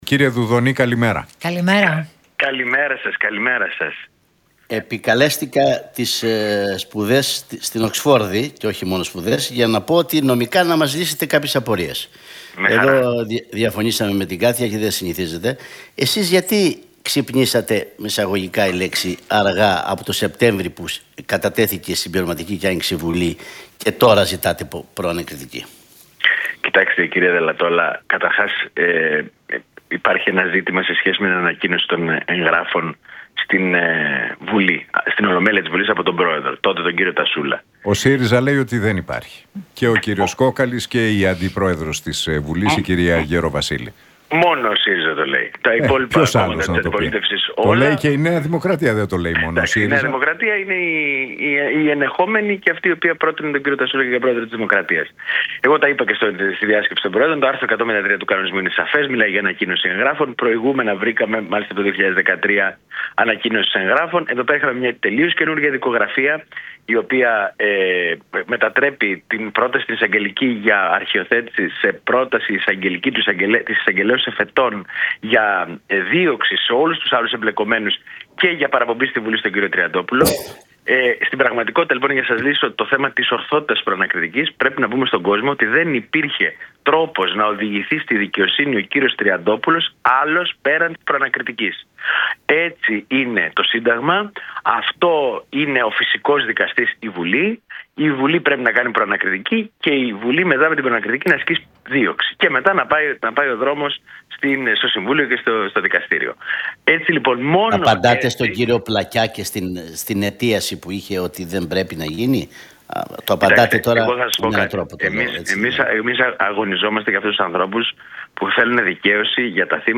από την συχνότητα του Realfm 97,8.